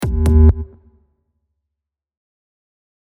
Buttons and Beeps
Error 6.mp3